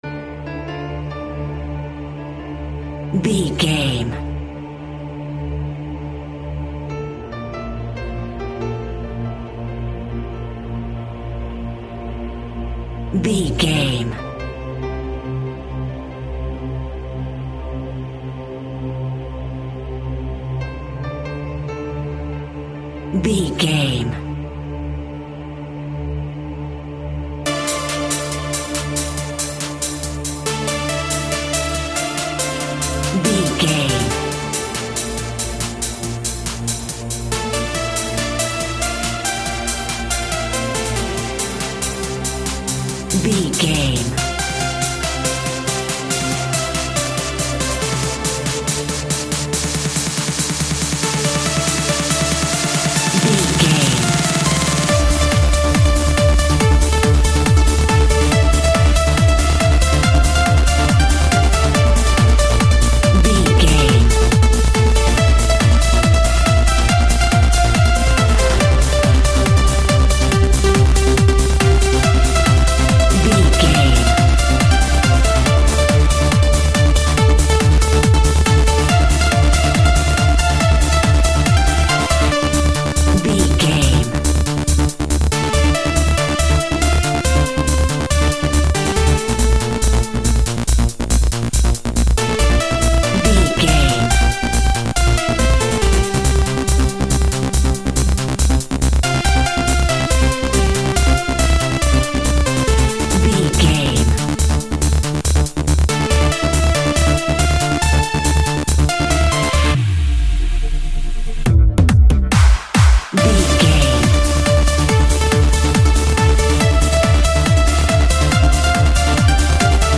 In-crescendo
Aeolian/Minor
D
Fast
driving
uplifting
hypnotic
industrial
drum machine
synths
uptempo
synth bass